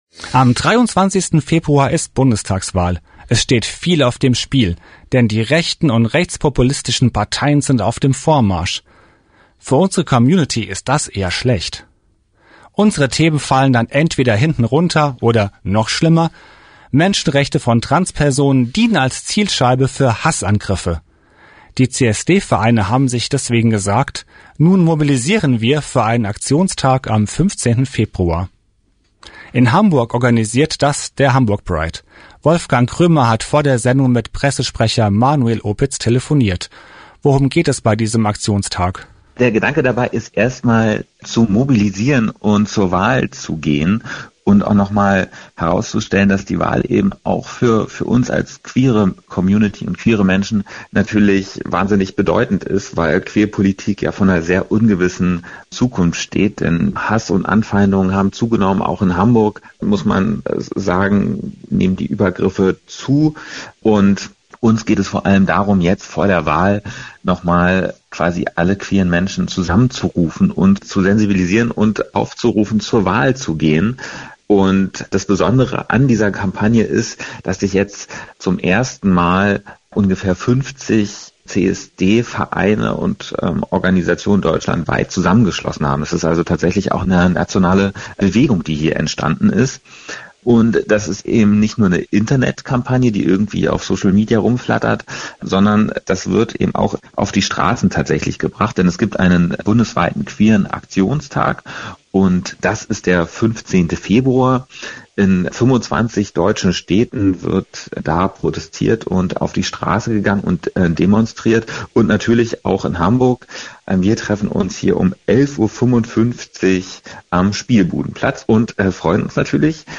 Der große Festsaal im Hamburger Rathaus war sehr gut besucht. Rednerinnen waren die Zweite Bürgermeisterin Katharina Fegebank und die grüne Außenministerin Annalena Baerbock (Foto Mi.).